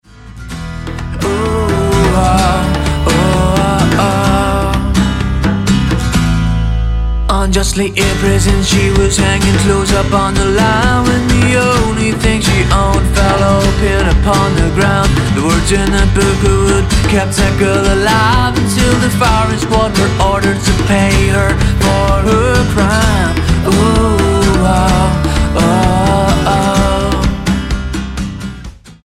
STYLE: Roots/Acoustic
acoustic guitar
deft percussion